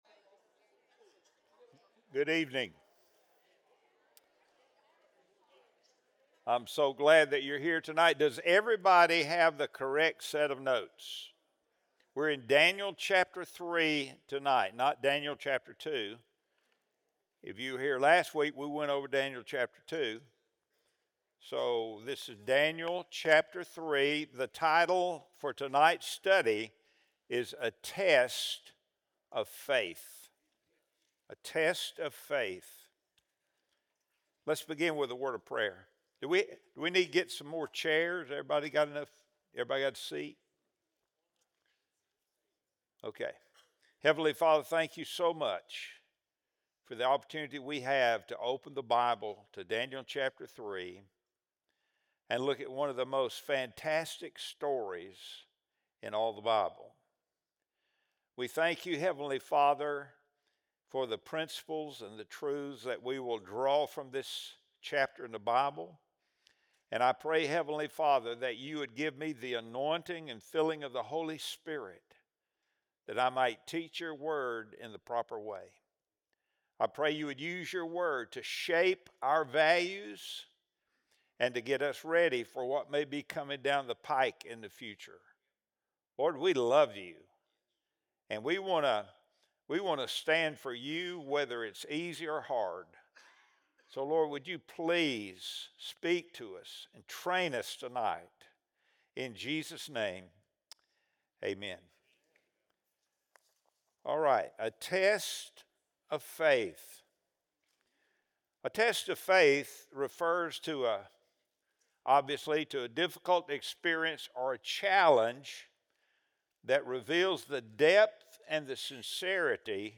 Wednesday Bible Study | October 1, 2025